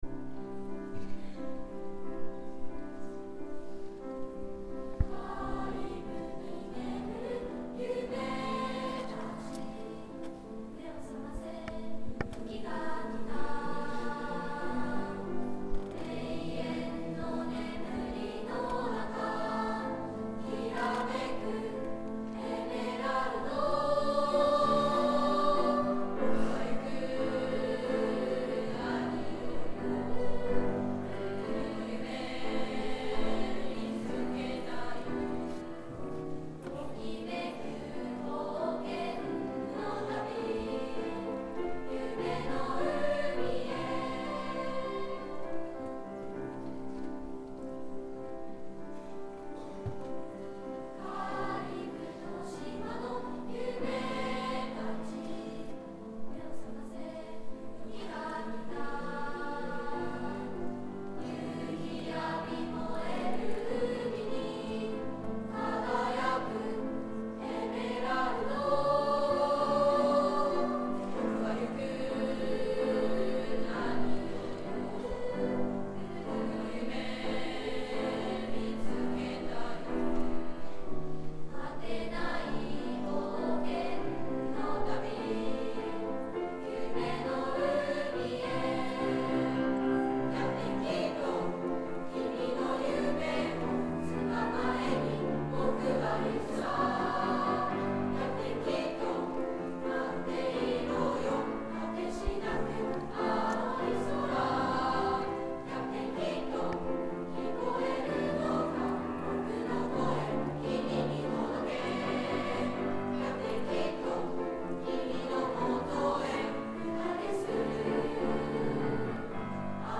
１Ｉ カリブ夢の旅.mp3←クリックすると合唱が聴けます